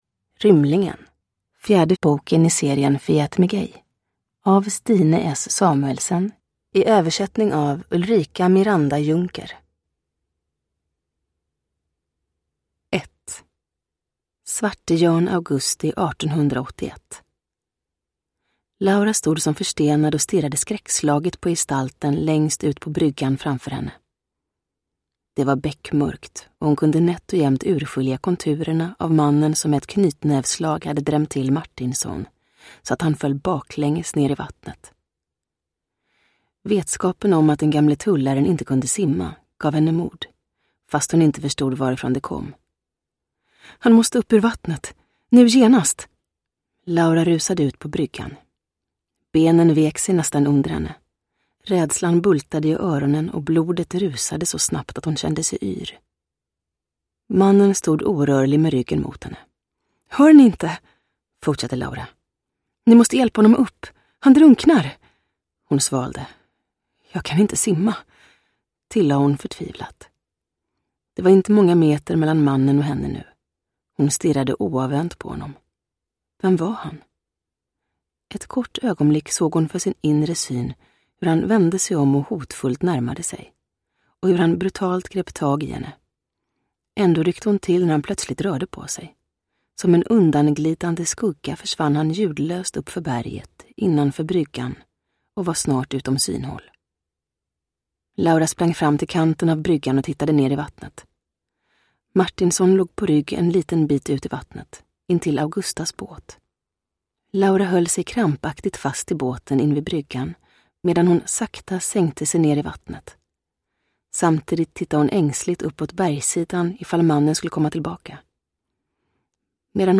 Rymlingen – Ljudbok – Laddas ner